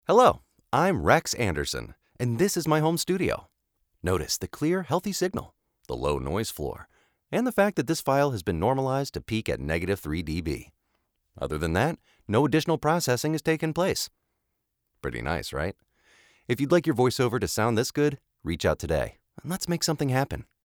American English Male Voice Over Artist
Male
Authoritative, Confident, Engaging, Natural, Warm
commercials.mp3
Microphone: Neumann U87ai, Sennheiser MKH416, AKG C414 XLS
Audio equipment: Lynx Aurora 8 converter, UA LA-610 preamp, CAPI VP26 preamp, Hairball Lola preamp